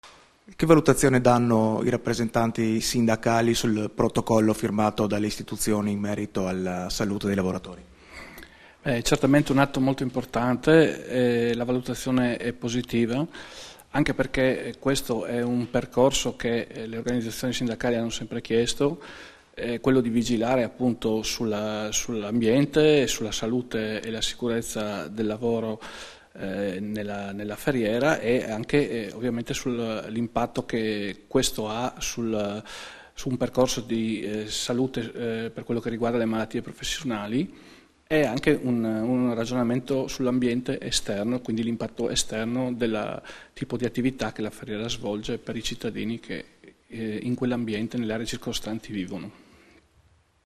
sul Protocollo per la prevenzione delle malattie professionali alla Ferriera di Servola, rilasciate a Trieste il 18 aprile 2016